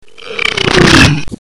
Rülpsen 4 26 KB 1588 Sound abspielen!